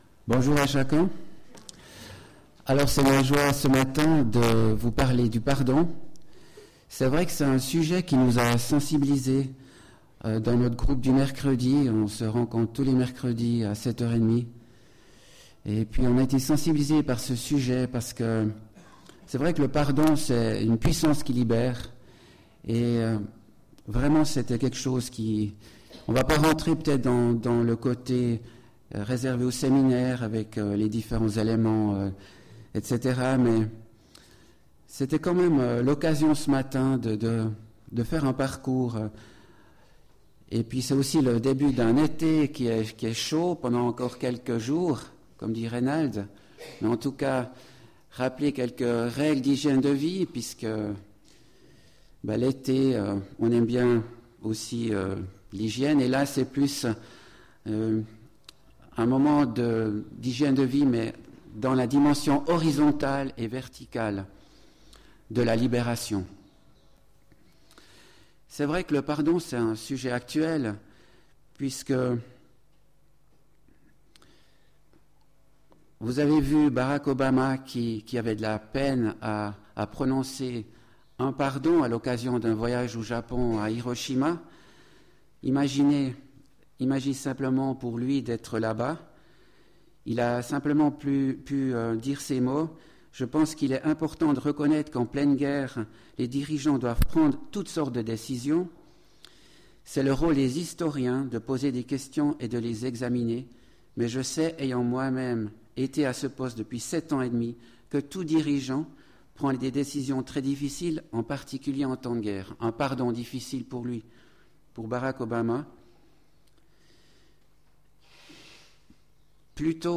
Culte du 10 juillet 2016 « Le Pardon »